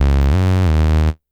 Snare_37.wav